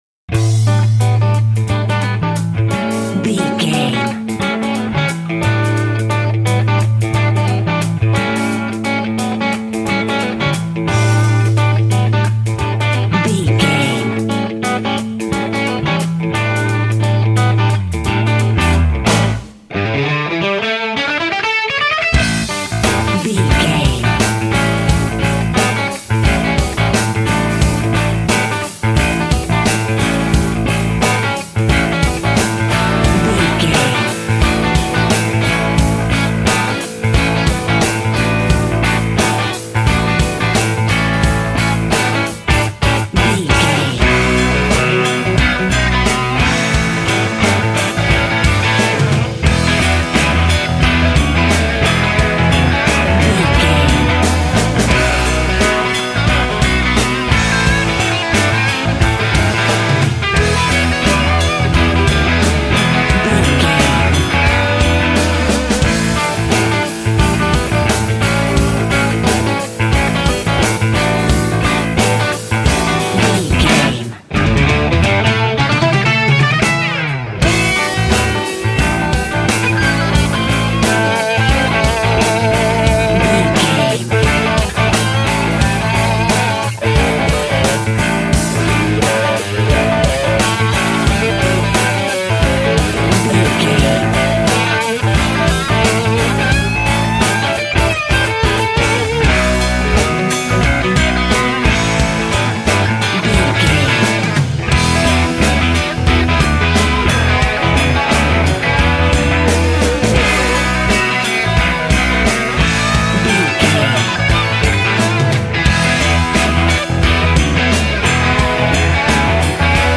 Ionian/Major
electric guitar
drums
bass guitar
pop rock
hard rock
lead guitar
aggressive
energetic
intense
powerful
nu metal
alternative metal